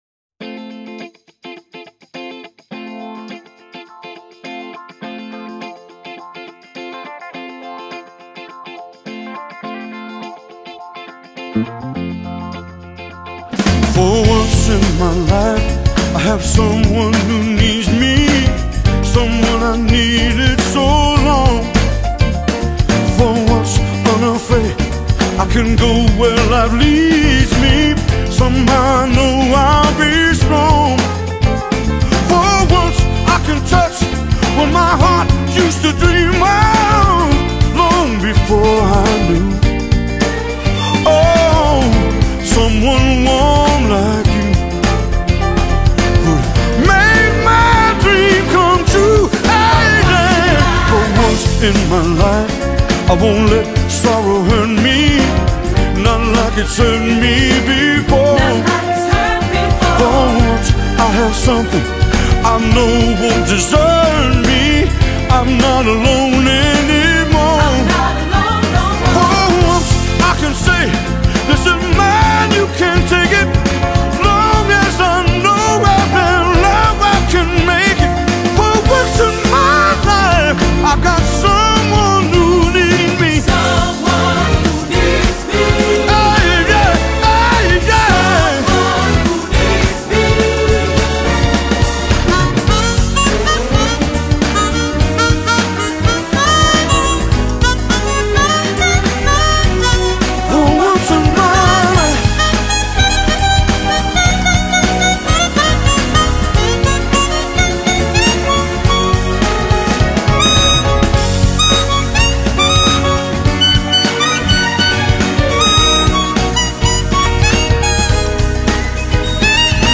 armónica